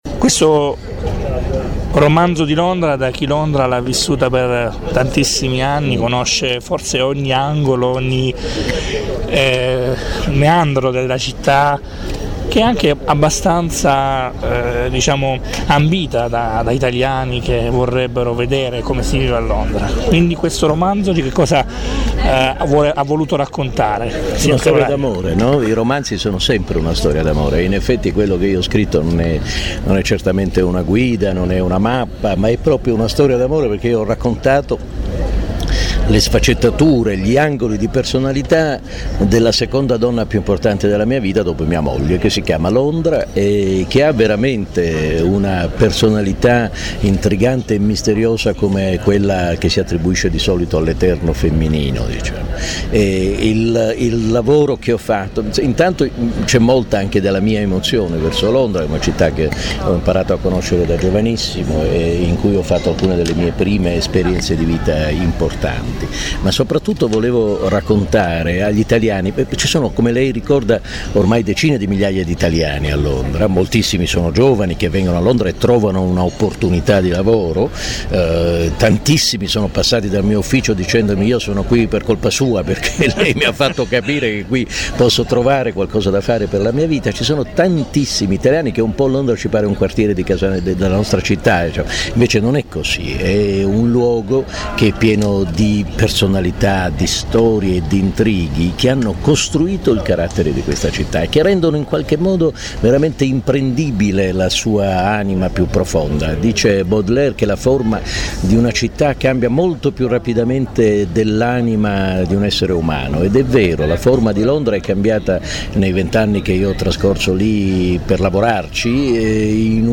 L’incontro con Antonio Caprarica si svolge nella cornice del Chiostro San Francesco.